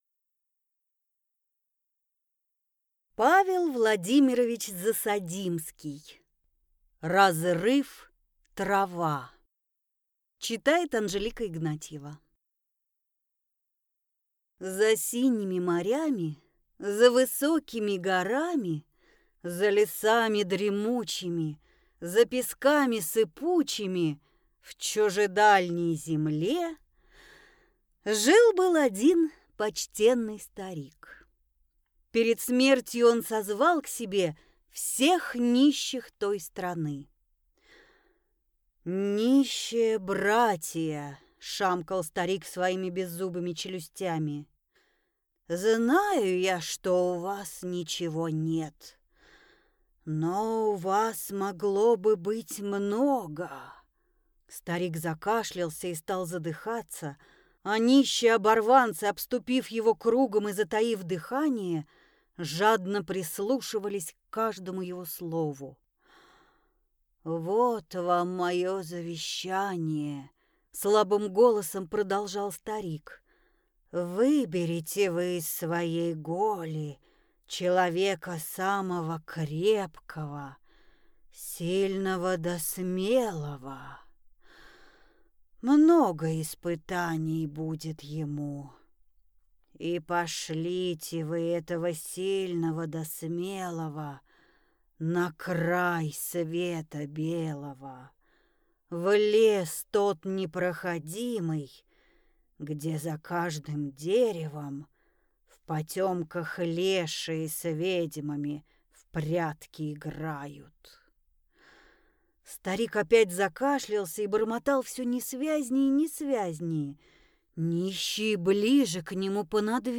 Аудиокнига Разрыв-трава | Библиотека аудиокниг
Прослушать и бесплатно скачать фрагмент аудиокниги